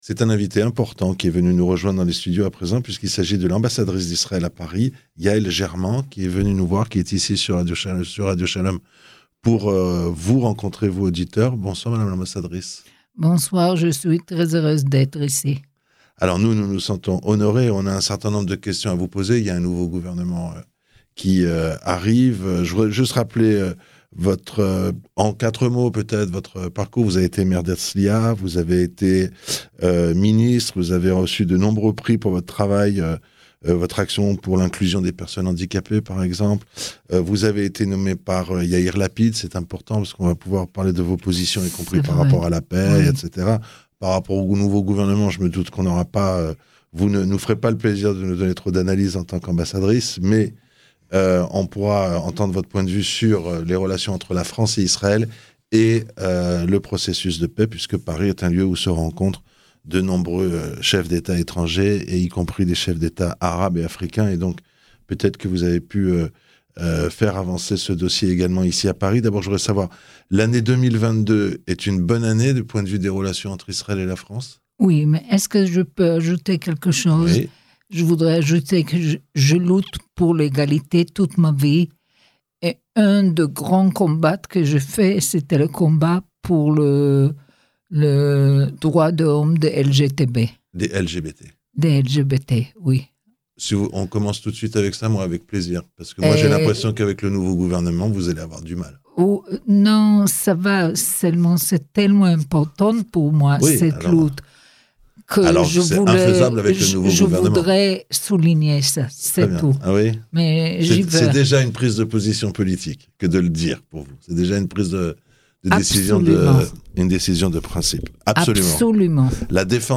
L'ambassadrice d'Israël en France, Yael German est venue rendre une visite à Radio Shalom pour l'une de ses dernières interviews en tant qu'ambassadrice.